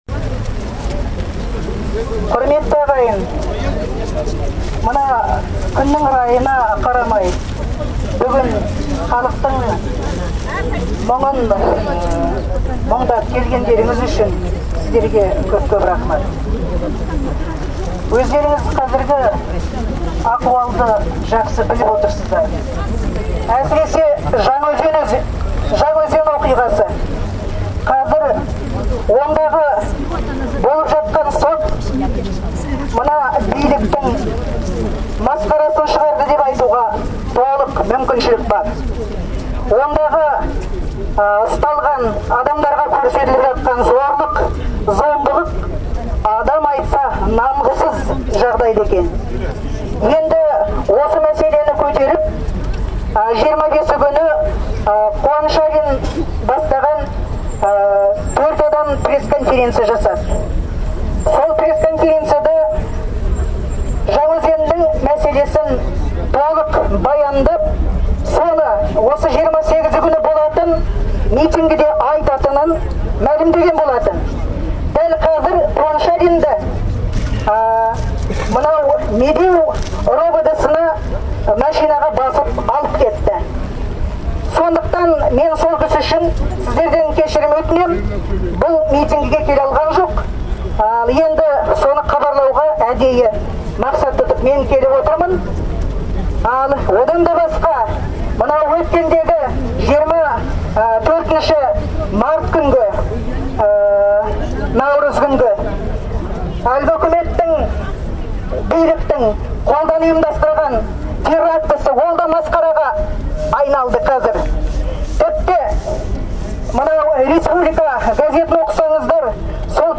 Қарсылық жиыны. Алматы, 28 сәуір 2012 жыл.